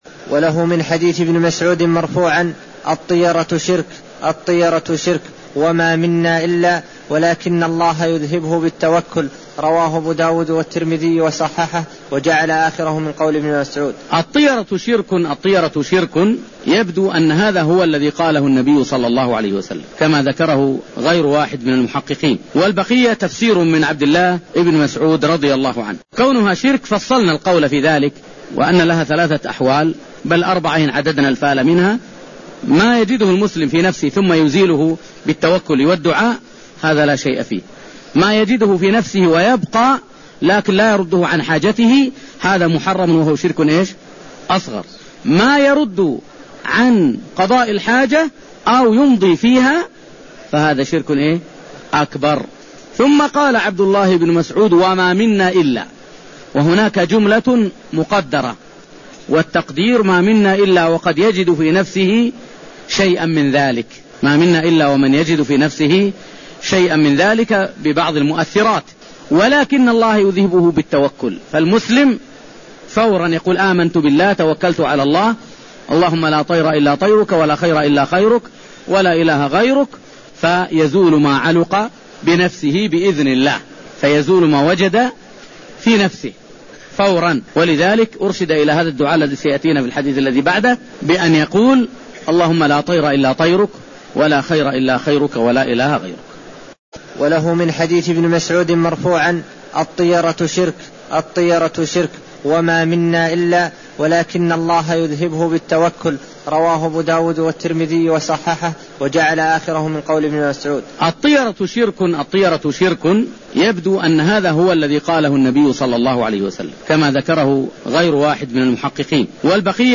الدرس 49